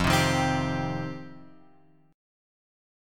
F7sus2 chord {1 3 1 x 1 3} chord